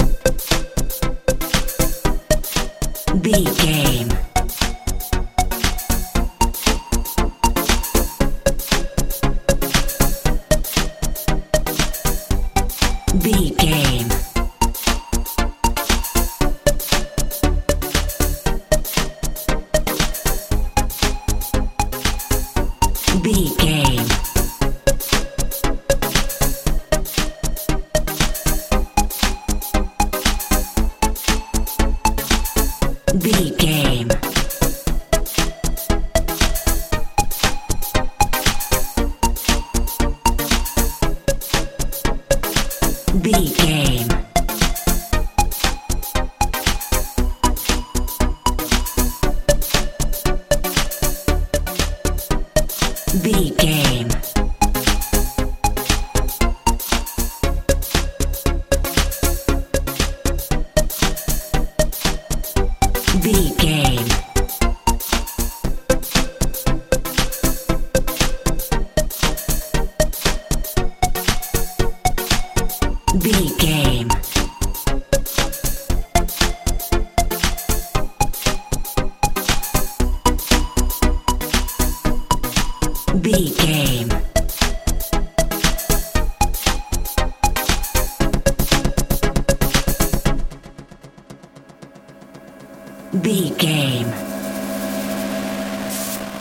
euro dance feel
Ionian/Major
joyful
happy
synthesiser
bass guitar
drums
80s
strange
suspense